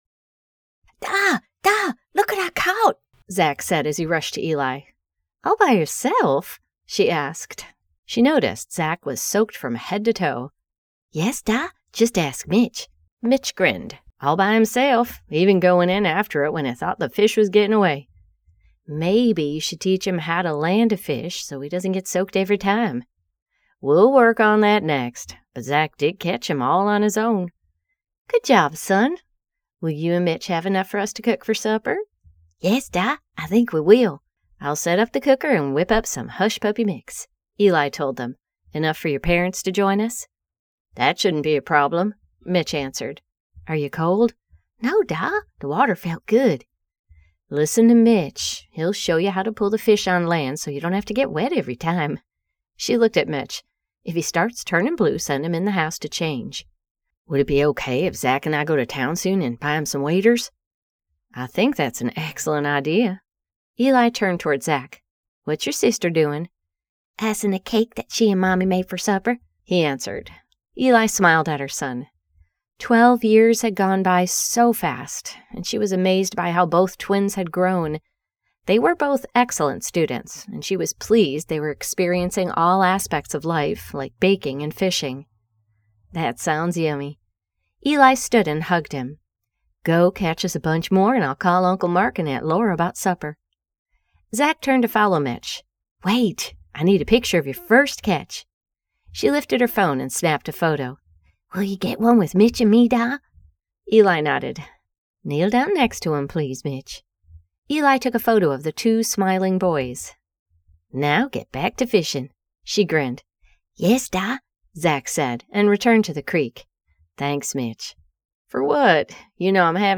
Turn the Page by Ali Spooner [Audiobook]
turn-the-page-5-min-promo.mp3